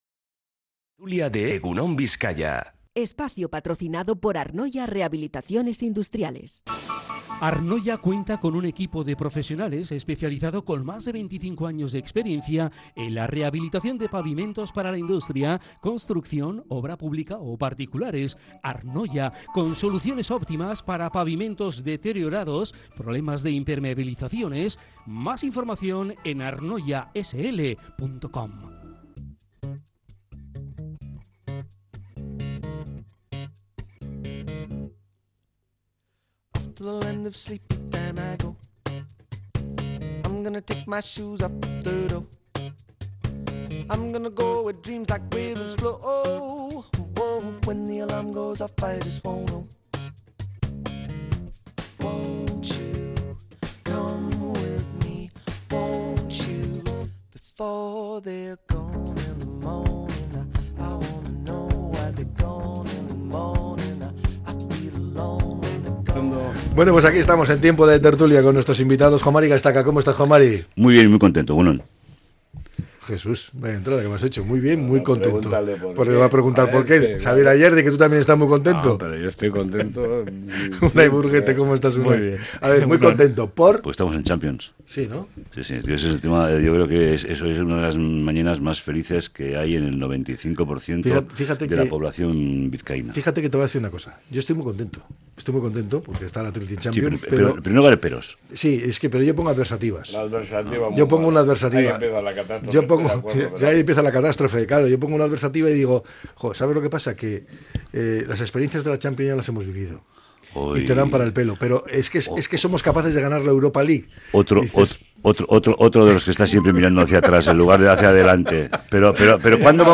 La tertulia